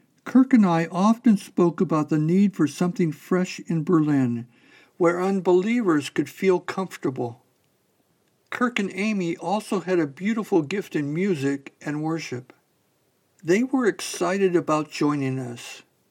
Here’s the same sample with a 2% Tempo increase.
What is the best order to apply the following Effects for my Audiobook recording?